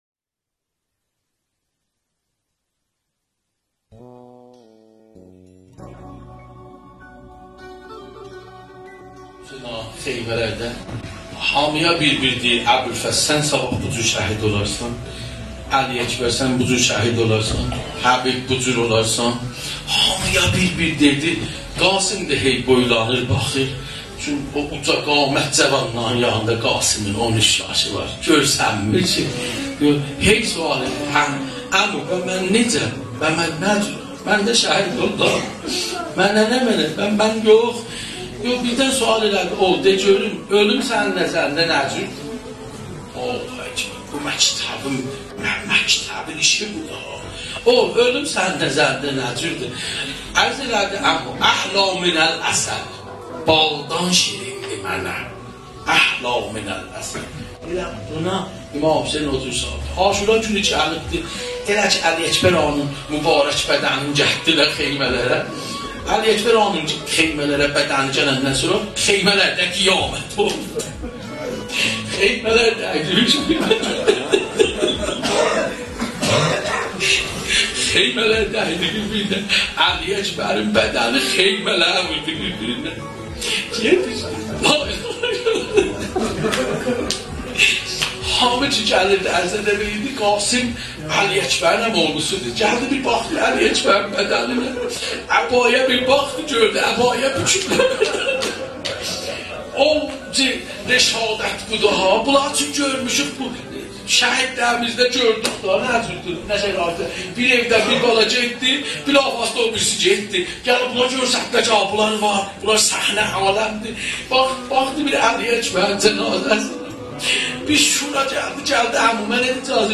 روضه حضرت قاسم بن الحسن علیه السلام
سخنرانی